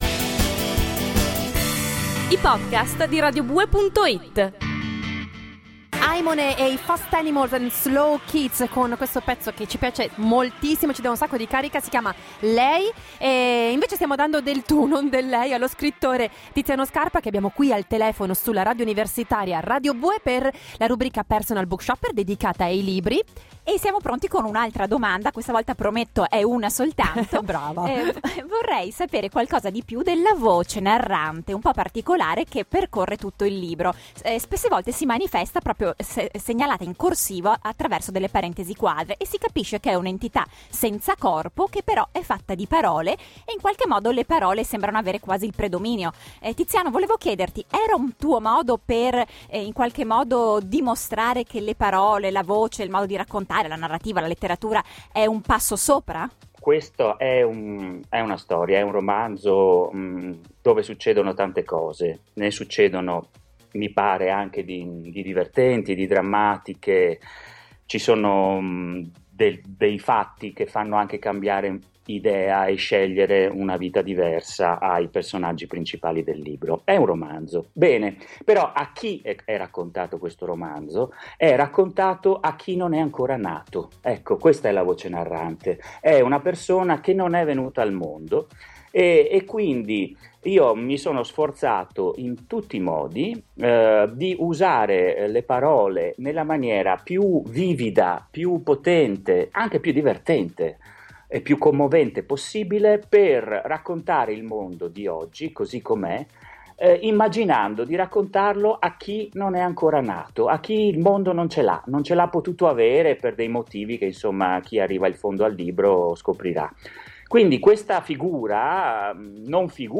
Udite udite, per l’ultima puntata radio prima delle vacanze estive, tiriamo fuori dal cilindro un’intervista a Tiziano Scarpa (Premio Strega 2009 per “Stabat Mater”) sul suo ultimo romanzo: “Il brevetto del geco” (Einaudi edizioni, collana Supercoralli, 328 pagine).